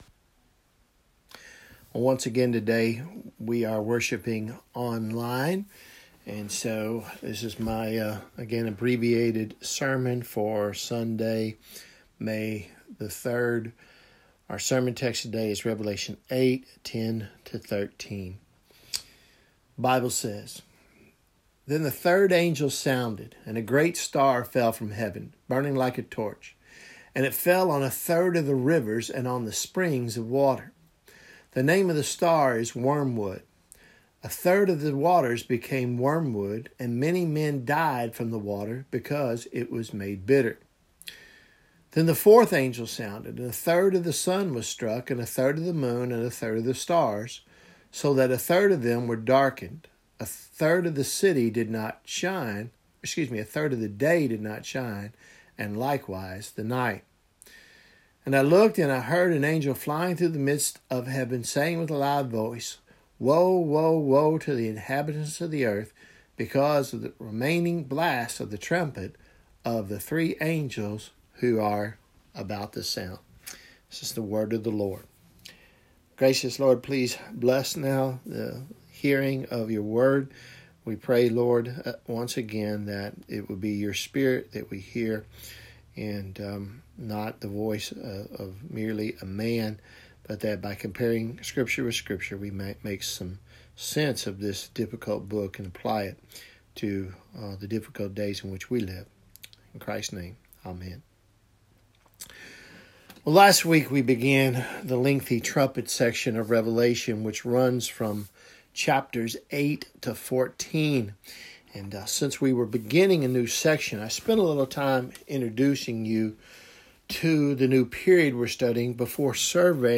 Revelation sermon series , Sermons May 03 2020 “Wormwood